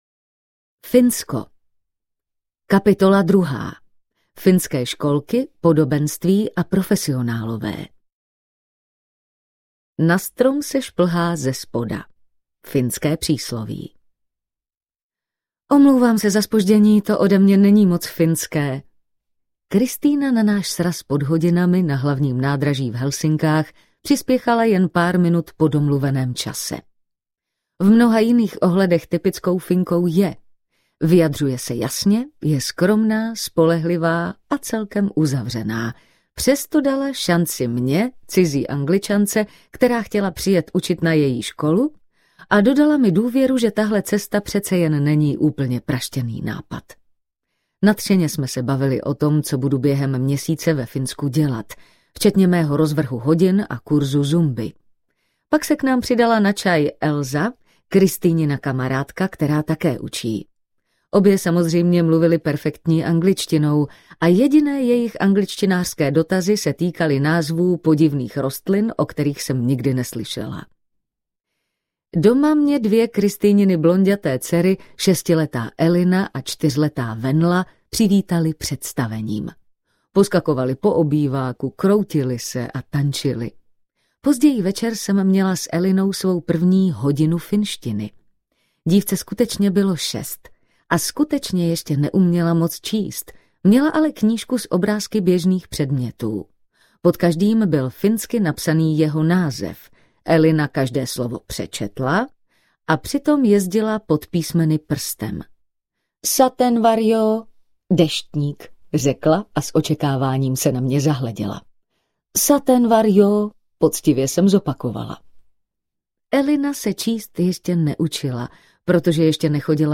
Audiokniha Chytrozemě